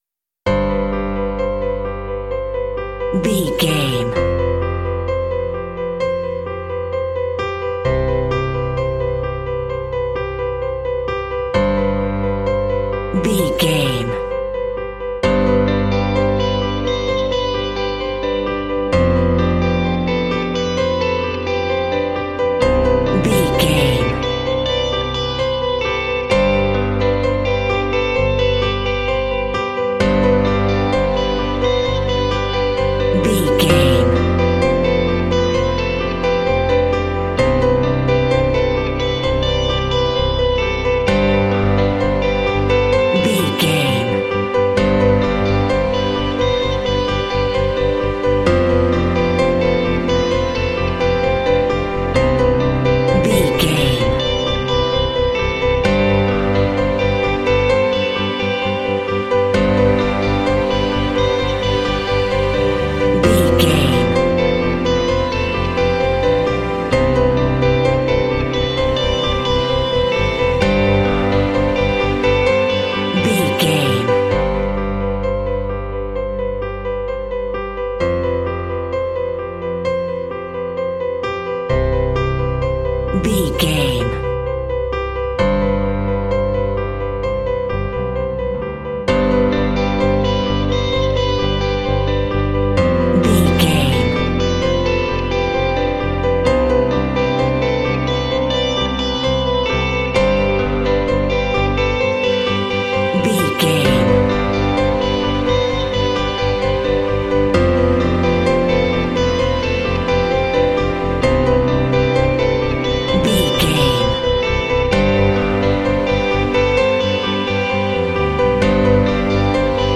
Epic / Action
Mixolydian
proud
inspirational
piano
electric guitar
strings
synthesiser
bass guitar
cinematic
symphonic rock